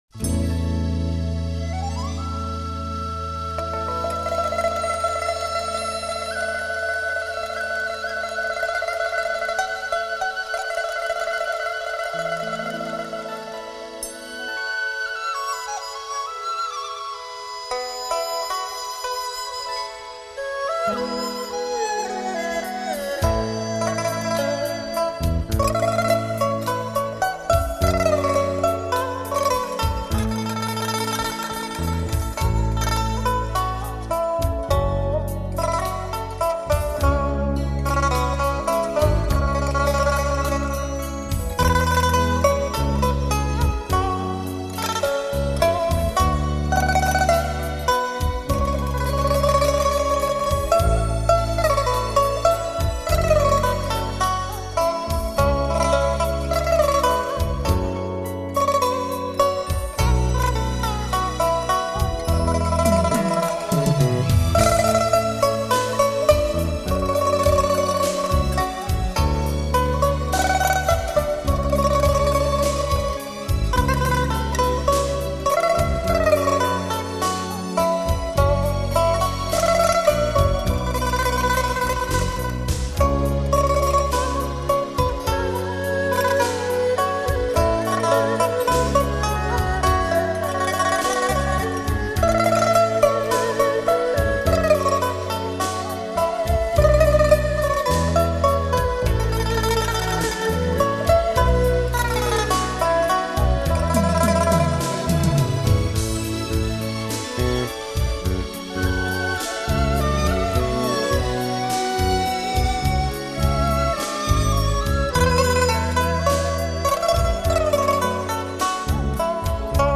民乐协奏轻音乐
弦乐队伴奏：二胡 板胡 高胡 笛子 古筝 琵琶 巴乌 中阮 轮番演奏